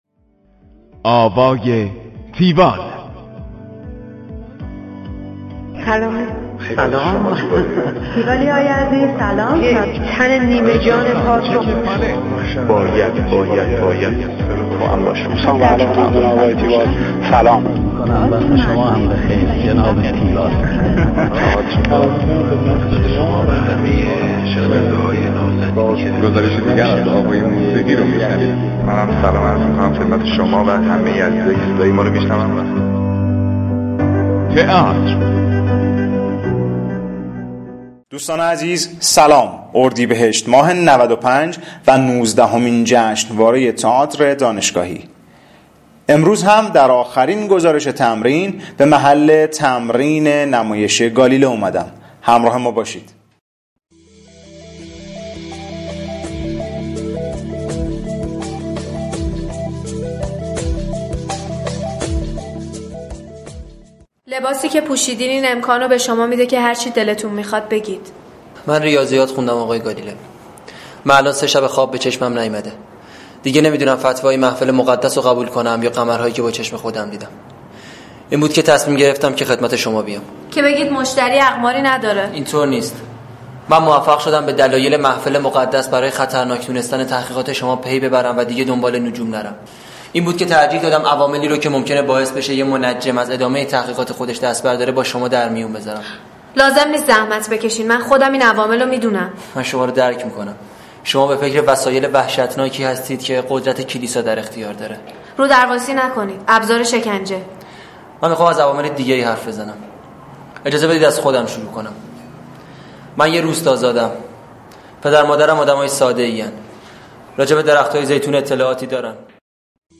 همراه با بخش هایی از تمرین